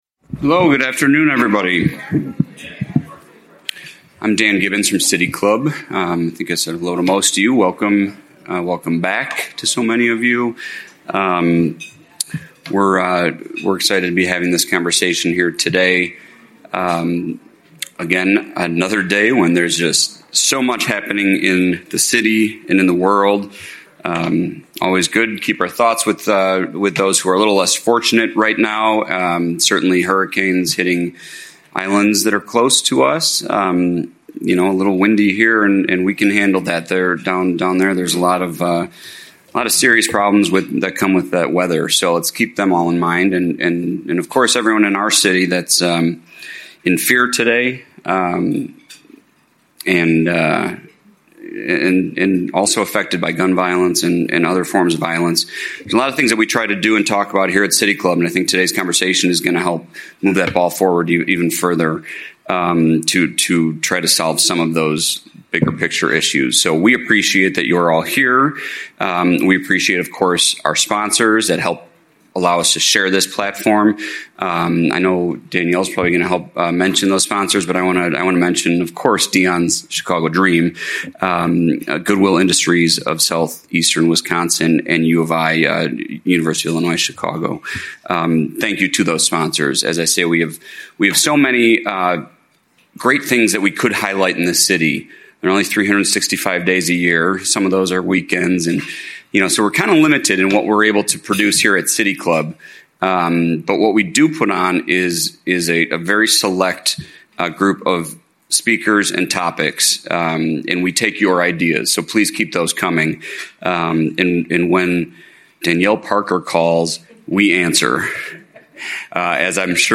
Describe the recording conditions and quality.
This conversation with Law And The Fam LLC “LATF” will focus on an emerging statewide model for fighting economic blight through entrepreneurship. LATF will present an economic impact framework with case studies highlighting best practices, followed by a panel discussion.